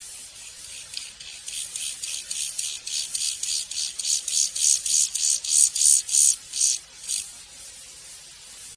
CHORTHIPPUS MOLLIS - ������������ �������